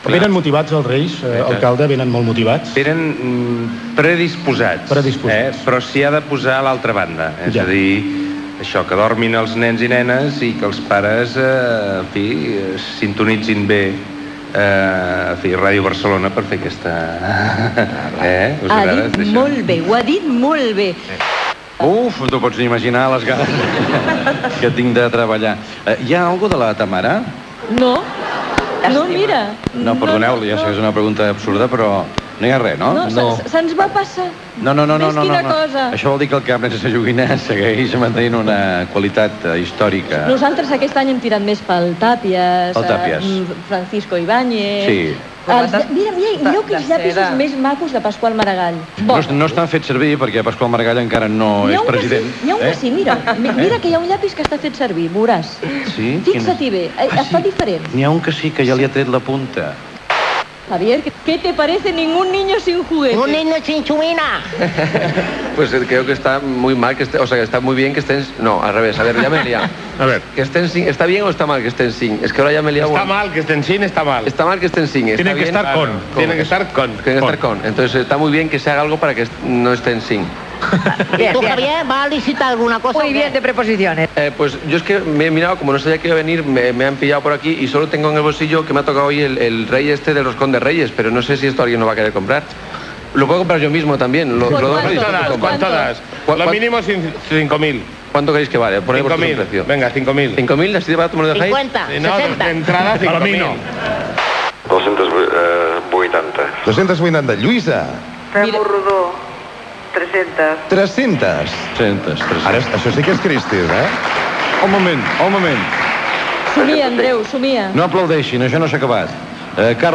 L'alcalde Joan Clos, el presentador Andreu Buenafuente que comenten alguns dels objectes licitats.